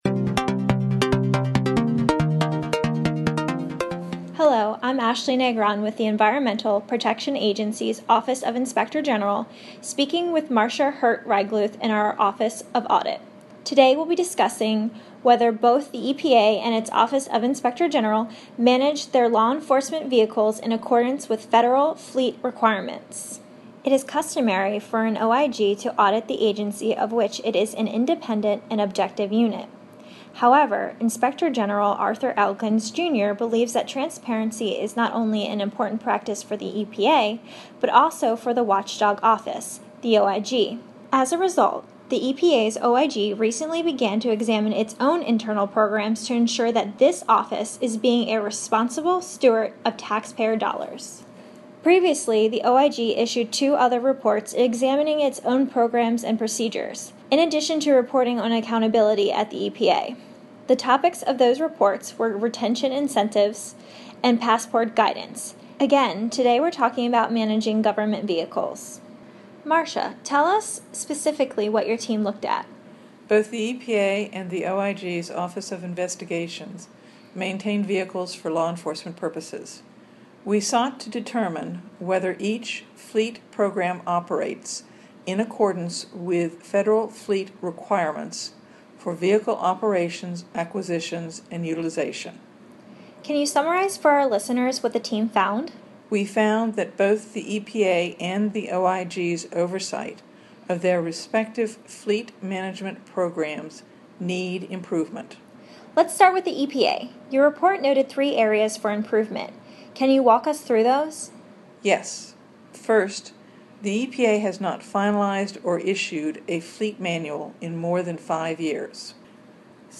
Listen to our staff talk about their latest audit, evaluation and investigation reports and other initiatives.